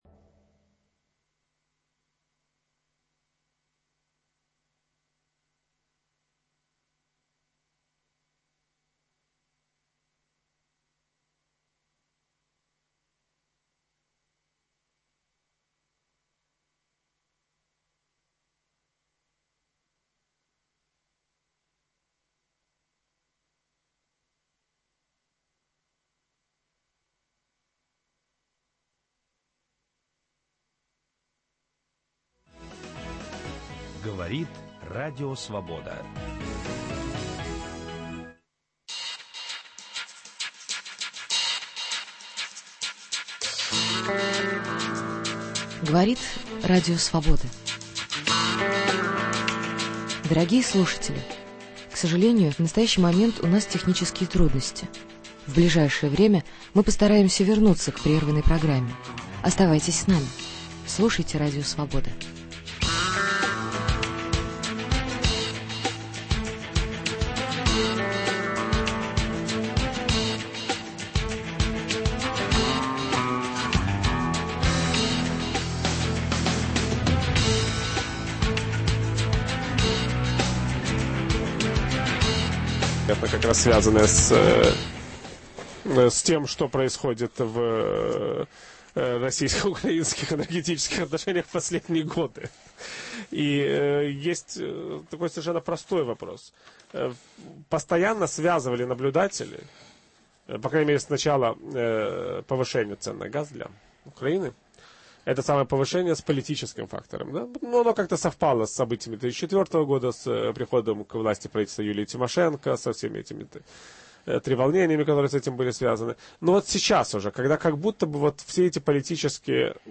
Новое украинское правительство и перспективы экономики страны. В программе участвуют журналисты украинских экономических изданий.